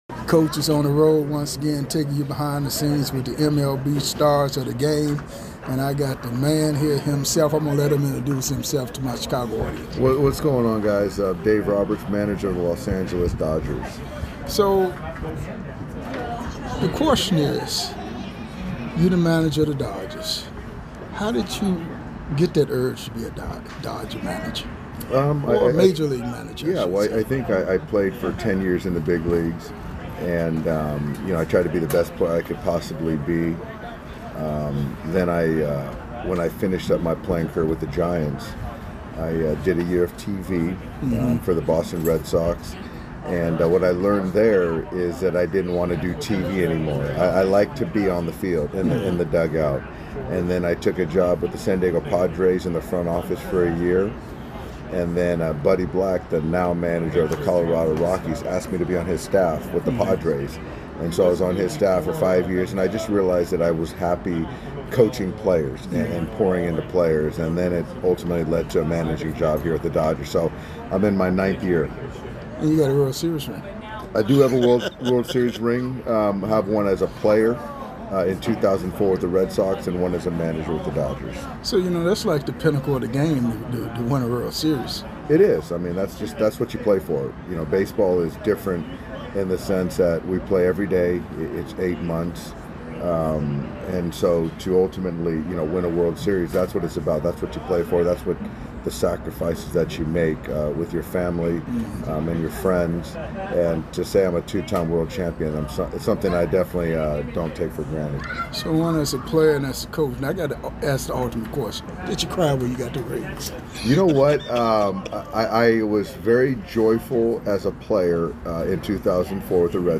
one on one with the MLB stars of the game